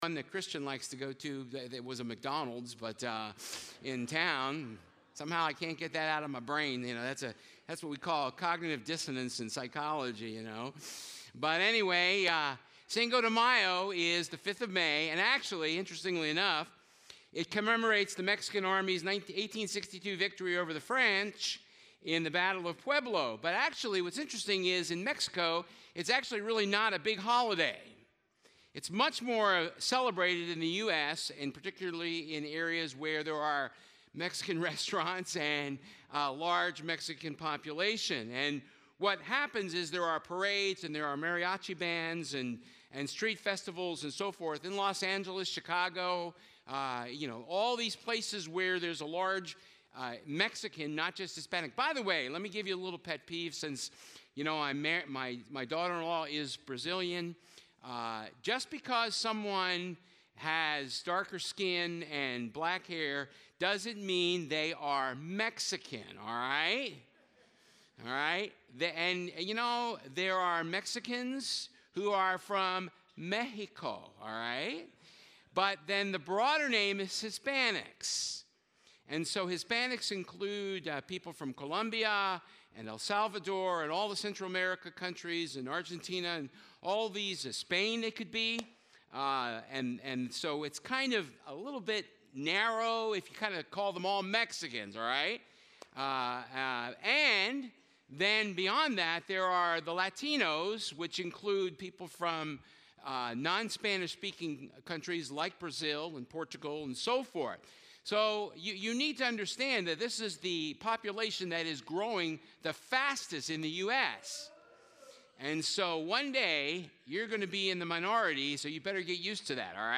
Hebrews 12 Service Type: Sunday Service Looking Back to Celebrate Reflection